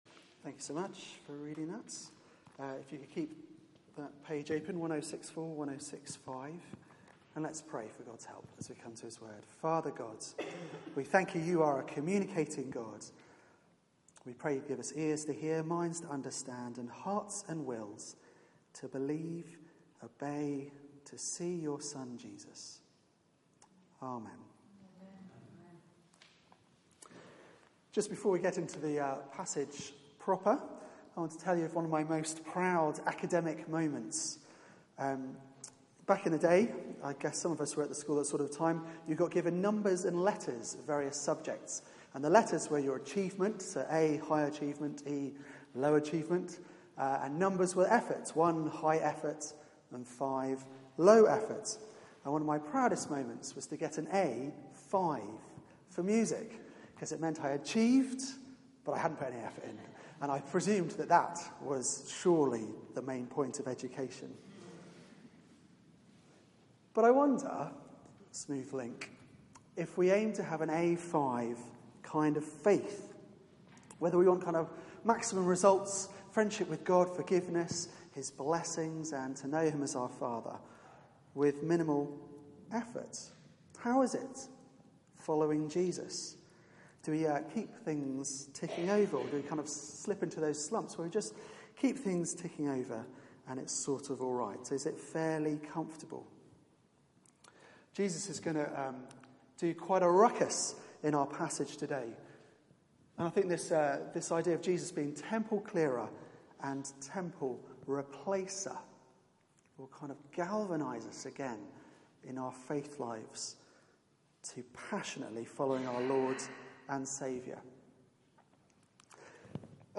Media for 4pm Service on Sun 19th Jun 2016 16:00
Theme: Temple Clearer, Temple Replacer Sermon